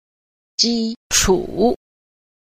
3. 基礎 – jīchǔ – cơ sở